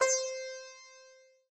sitar_c1.ogg